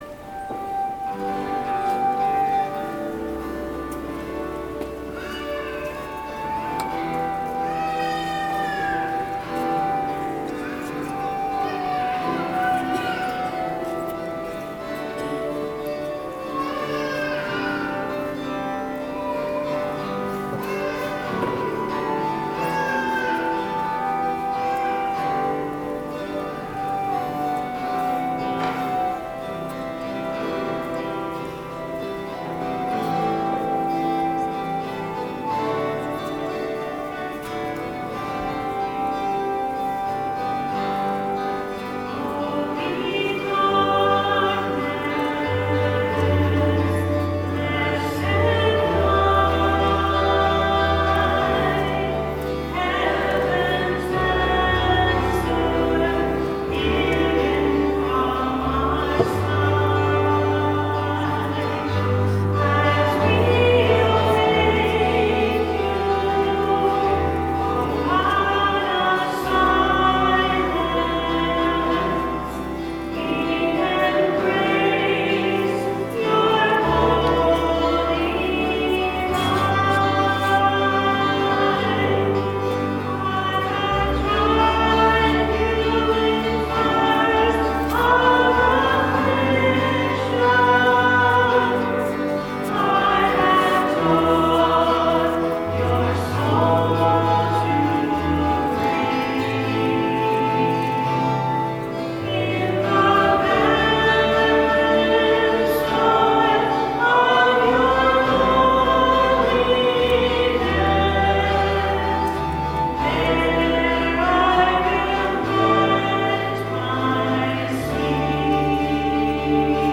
Music from the 10:30 Mass on the 1st Sunday in Lent, March 3, 2013: